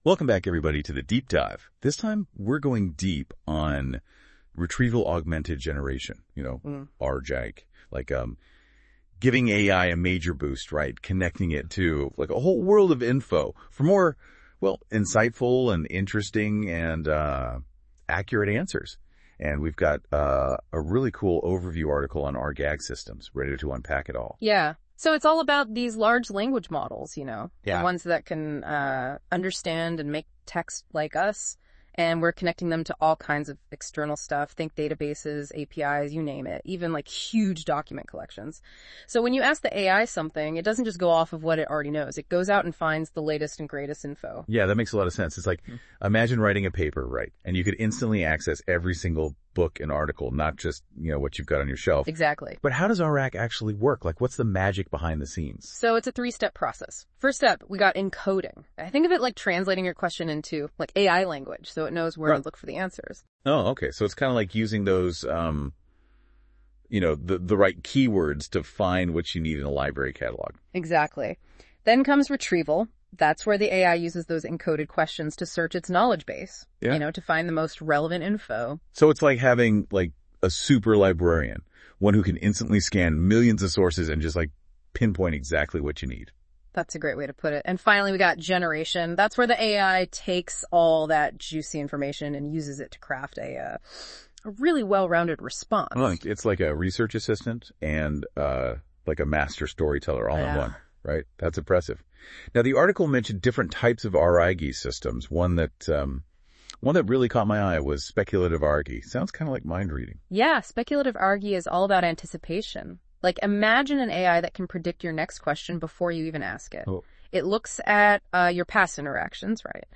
Listen to a NotebookLM-generated conversation of this article’s content • Download